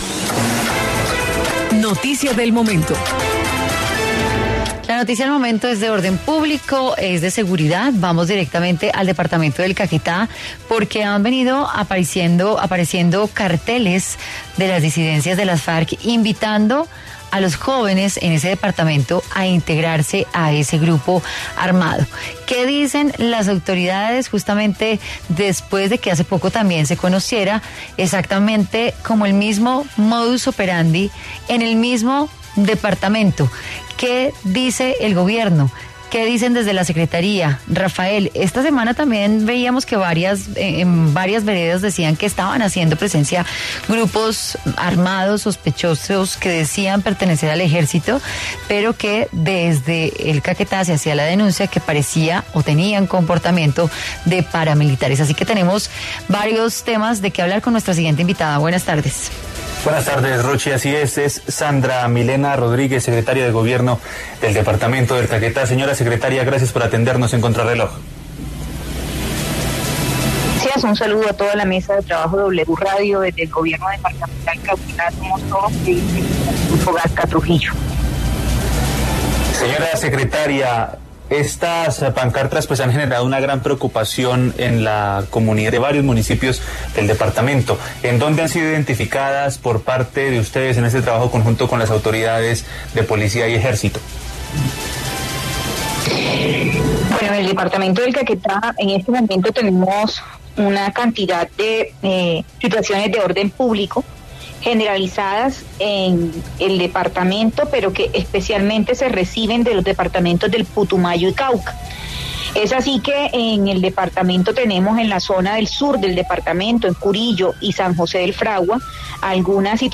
En entrevista con Contrarreloj, la secretaria de Gobierno del departamento del Caquetá, Sandra Milena Rodríguez, se refirió a la alerta por distintos pendones que han colocado las disidencias de las Farc (estructura Jorge Briceño) en vías del departamento, invitando a que jóvenes y otros ciudadanos se unan a ese grupo criminal.